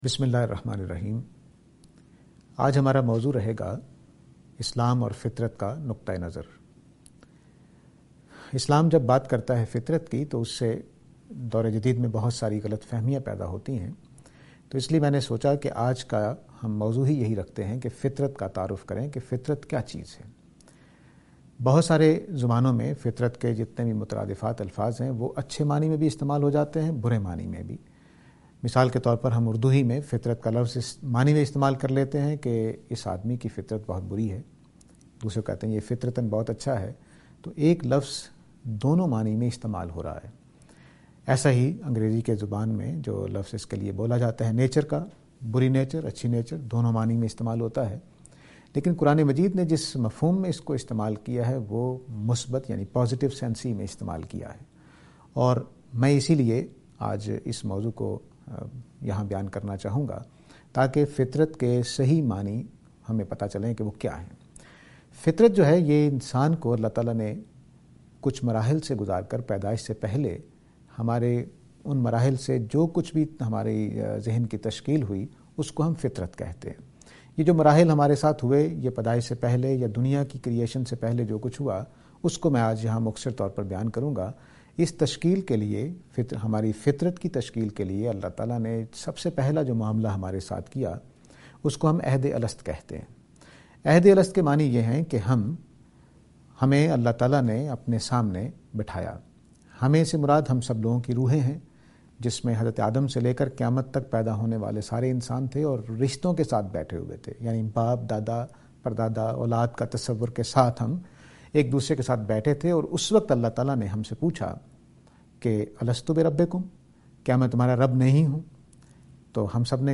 This lecture is and attempt to answer the question "Nature".